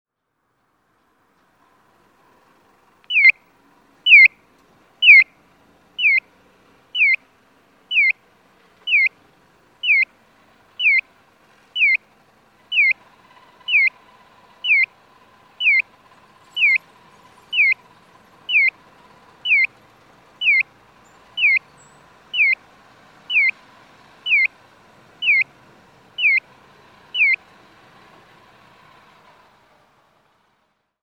交通信号オンライン｜音響信号を録る旅｜京都府の音響信号｜[0253]九条御前
九条御前(京都府京都市南区)の音響信号を紹介しています。